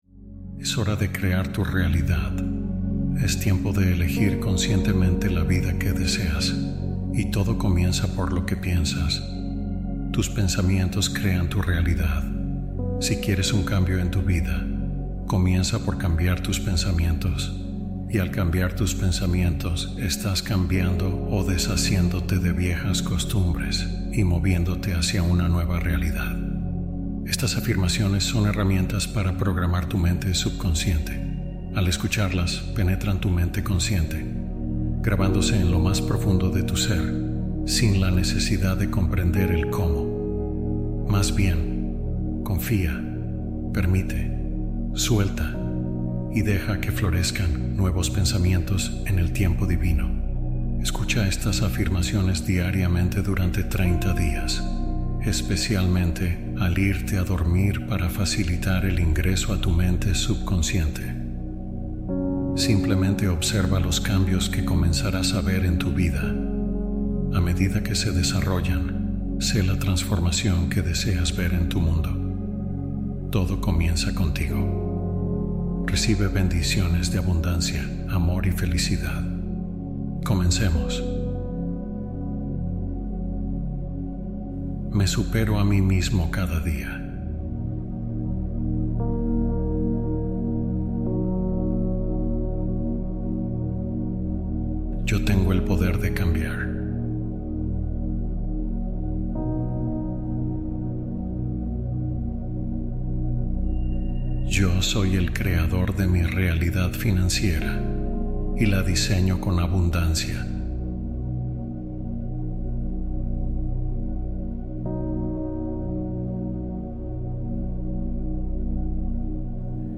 Salud y abundancia como intención: meditación nocturna